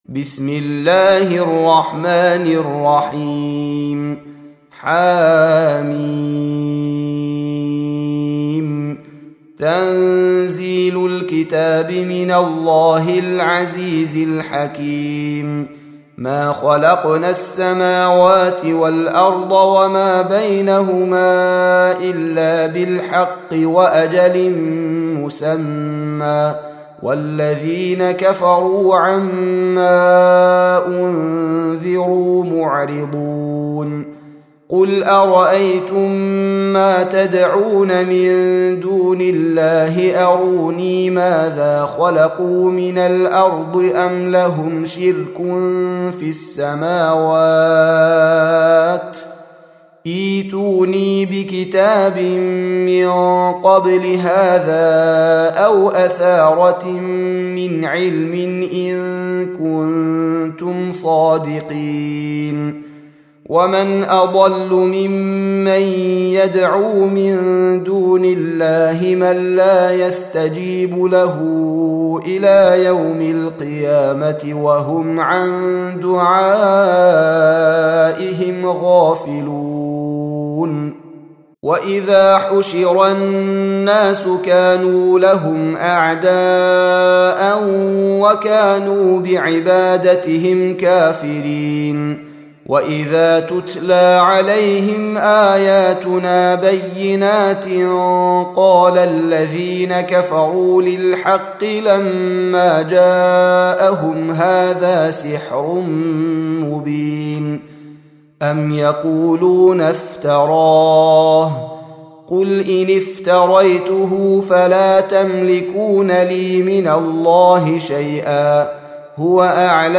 Audio Quran Tarteel Recitation